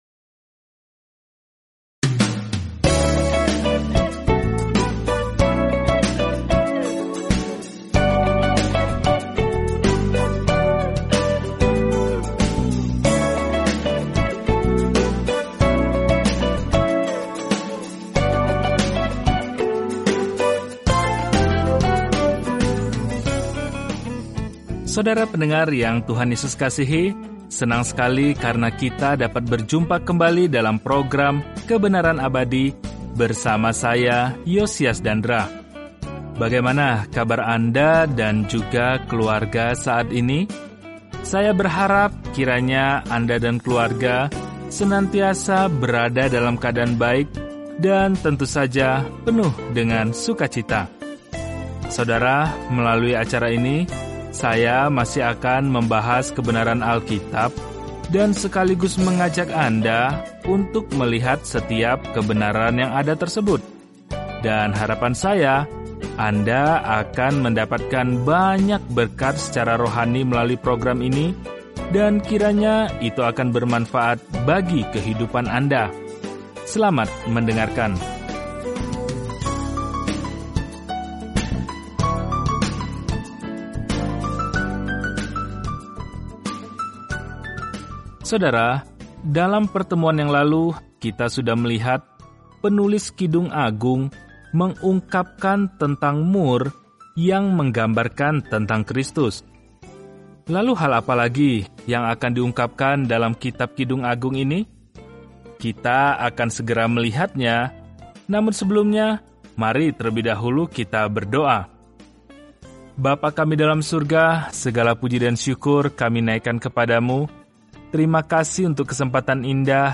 Firman Tuhan, Alkitab Kidung Agung 1:14-17 Hari 5 Mulai Rencana ini Hari 7 Tentang Rencana ini Kidung Agung adalah lagu cinta kecil yang merayakan cinta, hasrat, dan pernikahan dengan perbandingan luas dengan bagaimana Tuhan pertama kali mencintai kita. Perjalanan sehari-hari melalui Kidung Agung sambil mendengarkan studi audio dan membaca ayat-ayat tertentu dari firman Tuhan.